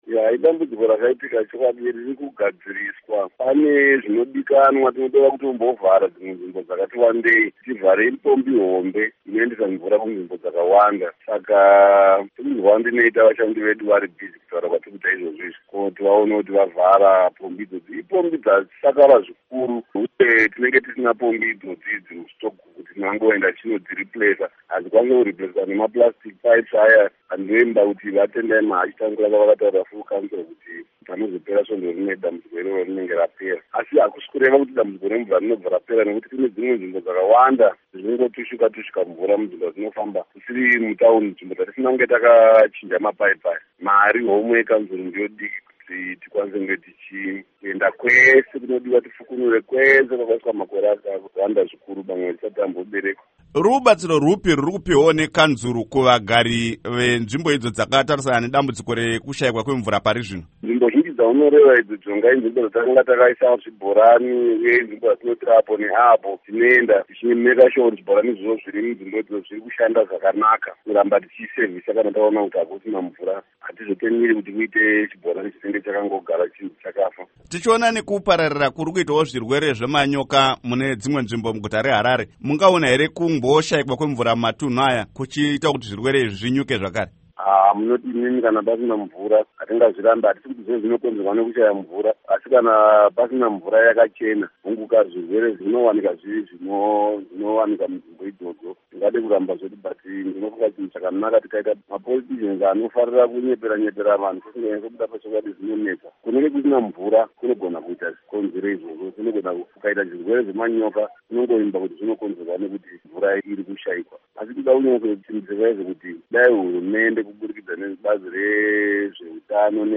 Hurukuro naVaEmmanuel Chiroto